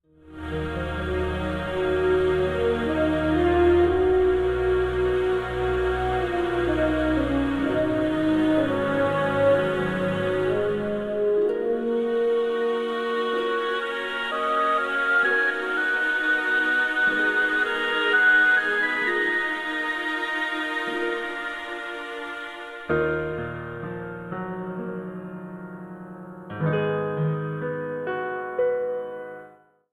This is an instrumental backing track cover.
• Key – E♭m
• Without Backing Vocals
• With Fade